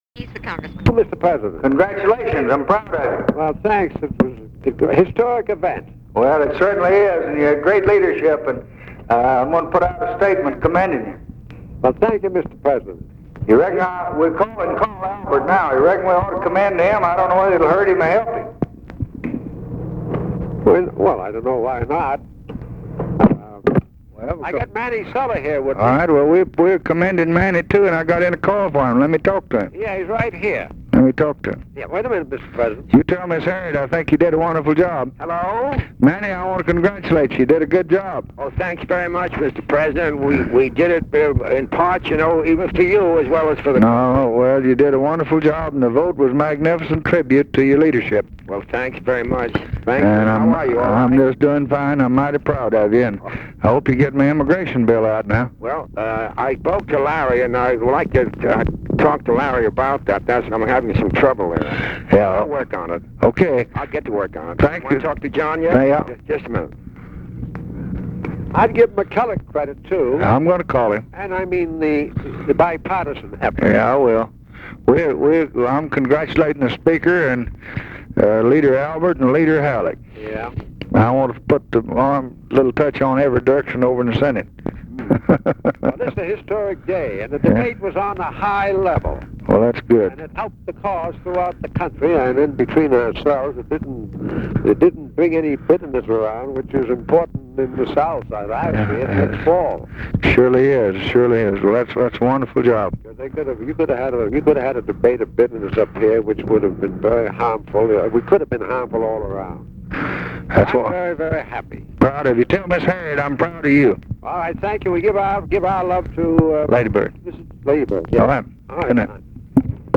Conversation with JOHN MCCORMACK and EMANUEL CELLER, February 11, 1964
Secret White House Tapes